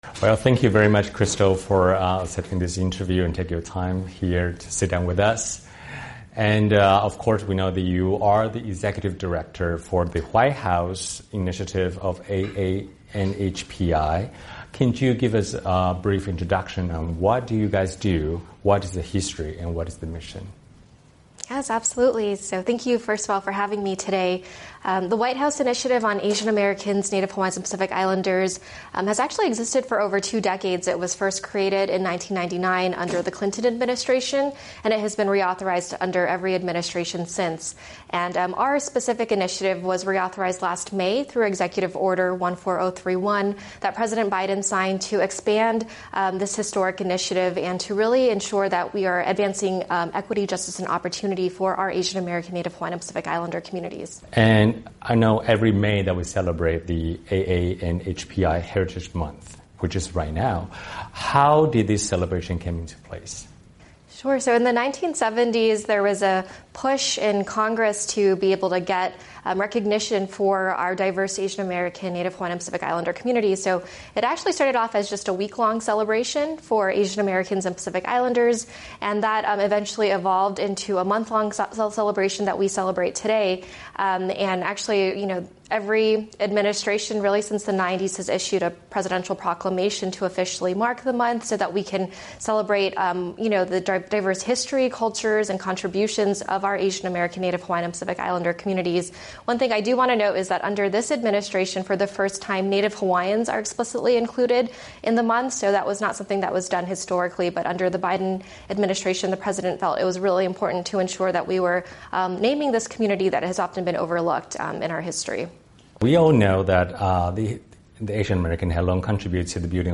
下面请看专访。